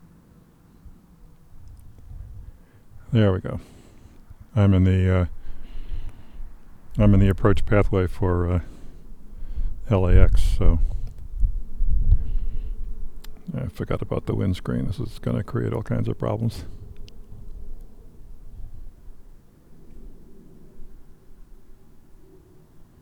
The second clip is volume adjusted and a rumble filter applied to get rid of the wind noise.
I didn’t do anything out of the ordinary except tell the Zoom recorder to pay attention to the external microphones instead of its own built-in ones.
I’m standing outside for this capture. That’s what my neighborhood sounds like at 17:30.